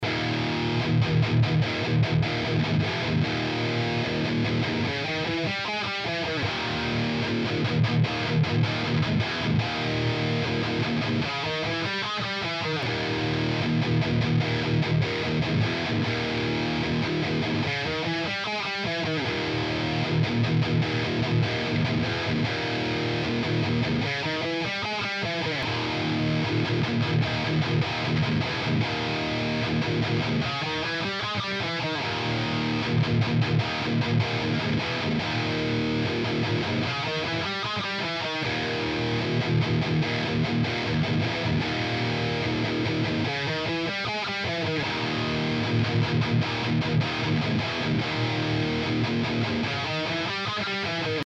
Zum Thema Pickups: Dieses Soundbeispiel habe ich 2020 aufgenommen.
Der Sound hat auch richtig Power.